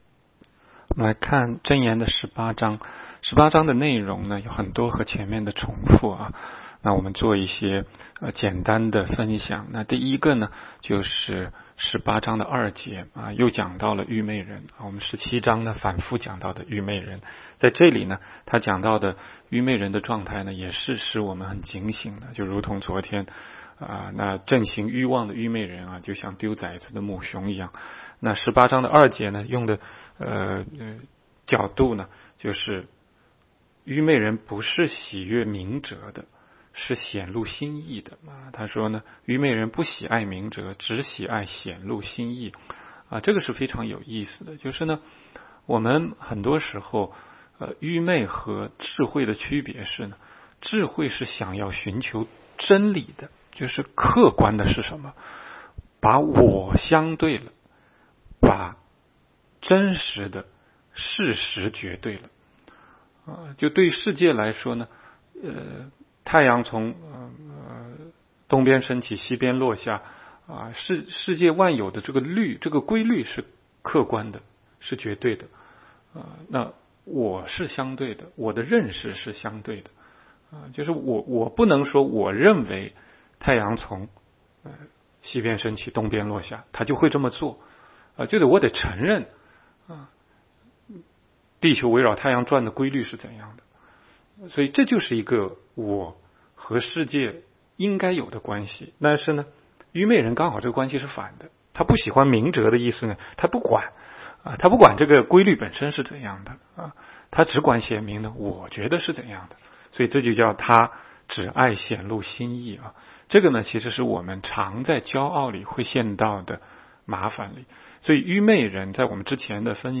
16街讲道录音 - 每日读经 -《 箴言》18章